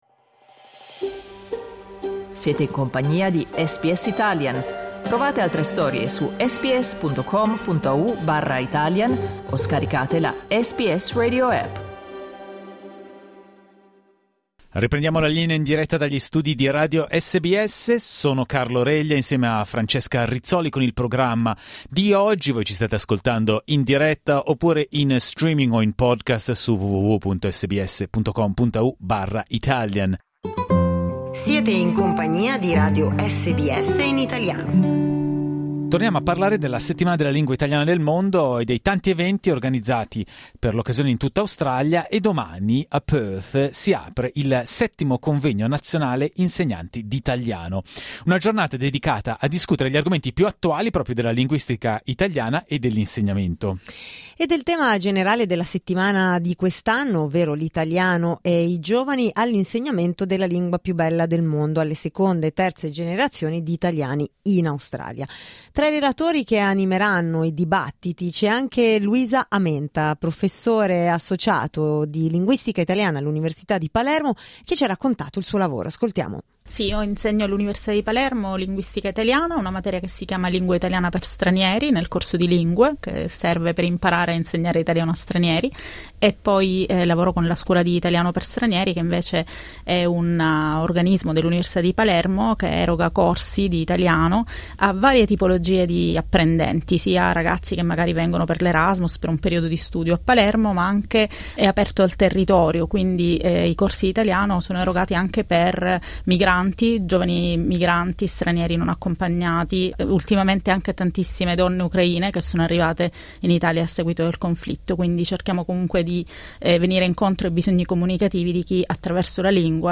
Ai microfoni di SBS Italian, la professoressa ha raccontato il suo lavoro a contatto con studenti universitari stranieri, rifugiati e minori non accompagnati e come questa diversità di bisogni - che si riflette nella pluralità che caratterizza la realtà quotidiana, per le strade della sua Palermo- possa arricchire la lingua italiana di oggi.